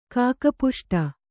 ♪ kāka puṣṭa